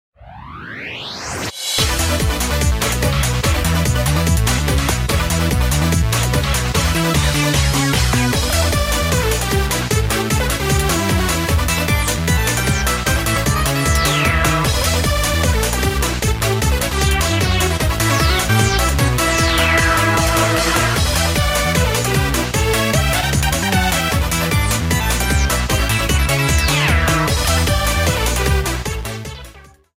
arrangement
Fair use music sample